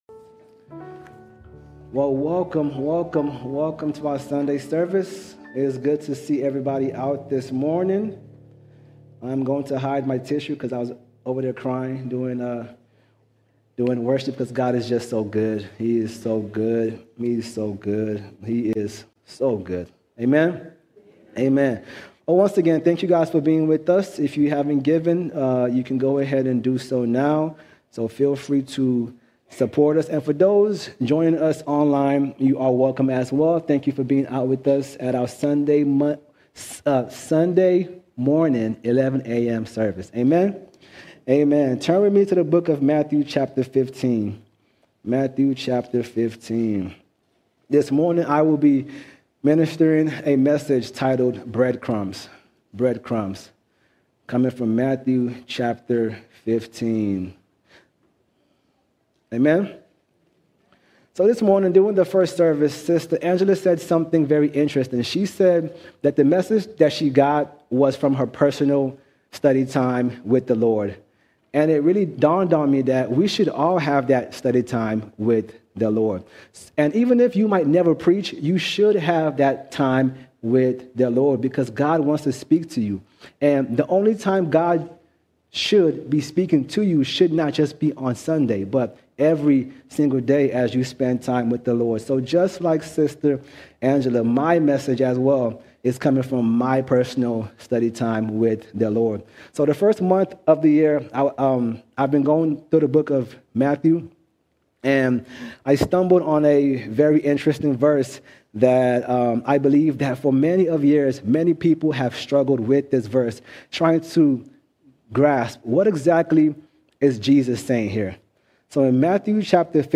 9 February 2026 Series: Sunday Sermons All Sermons Breadcrumbs Breadcrumbs Jesus’ words about “little dogs” often trouble readers, but the passage reveals something deeper.